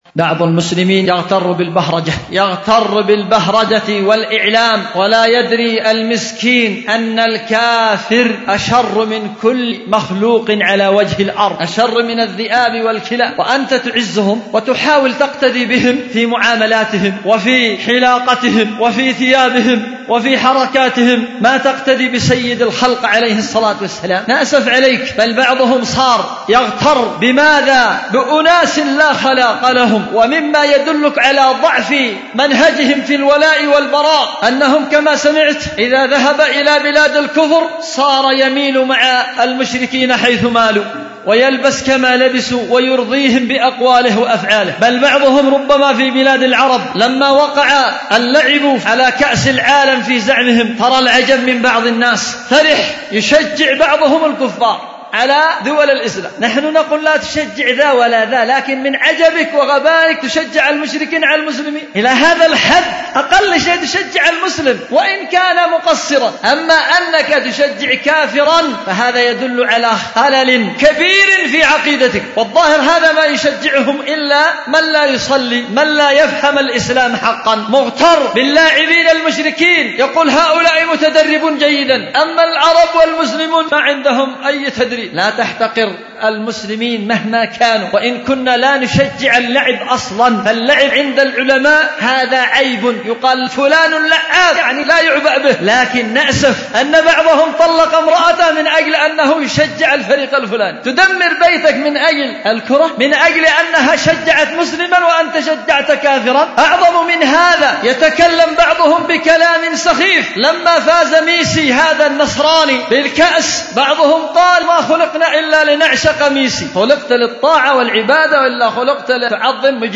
دار الحديث بمسجد السلف الصالح بمدينة ذمار